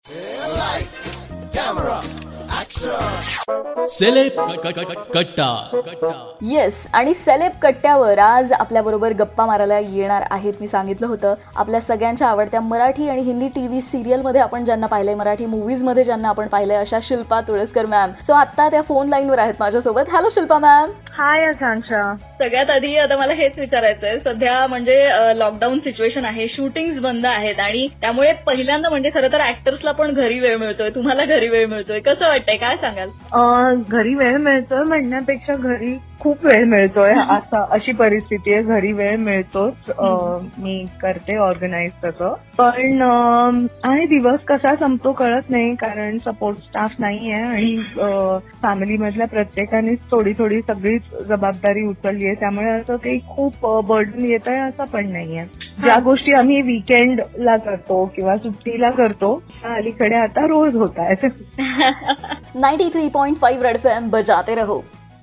In this interview Shilpa Tulaskar shared her experience of lockdown period.